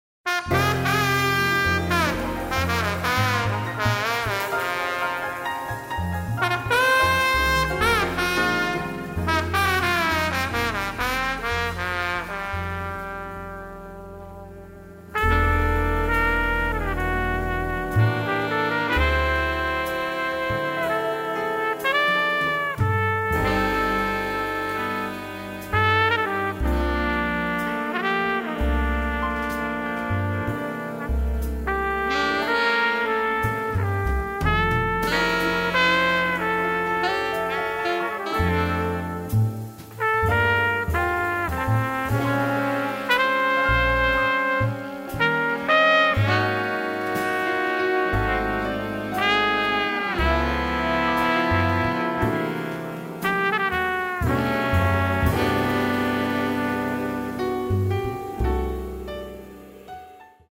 trumpet, keyboards